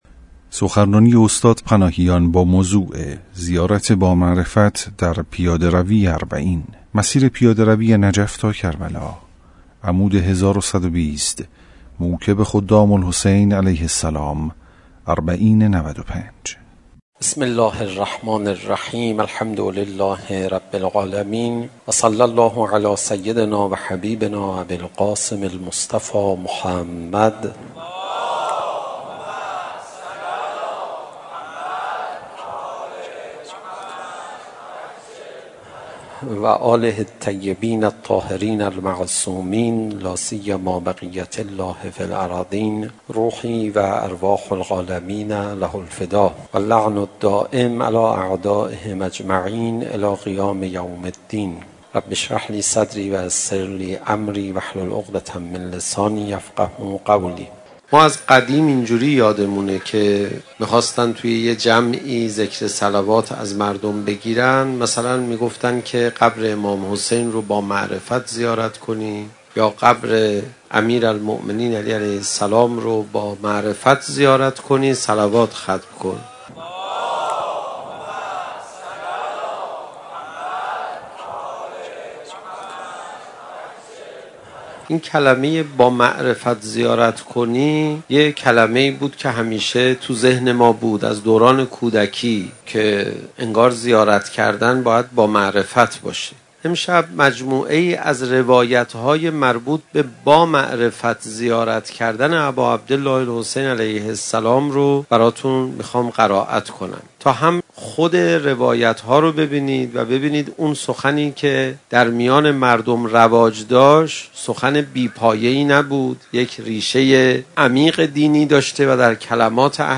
صوت | زیارت با معرفت در پیاده‌روی اربعین ( مسیر پیاده ‌ روی نجف به کربلا - عمود 1120 - موکب خدام‌الحسین(ع) - ۹۵.۸.۲۴)
اینک سؤال مهم این است که معنای «معرفت به حق امام حسین(ع)» چیست؟ حجت الاسلام پناهیان در این سخنرانی به این سؤال پاسخ می‌دهد.